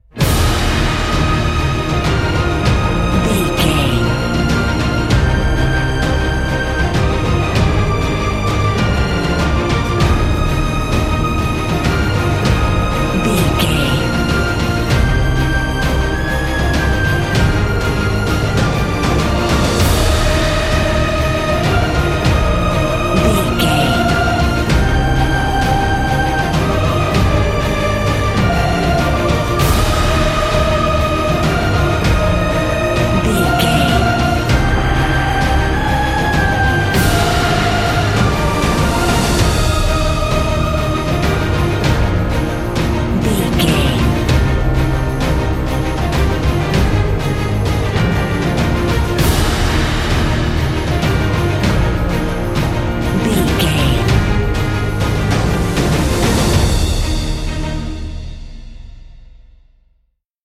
Uplifting
Ionian/Major
E♭
cinematic
powerful
brass
drums
strings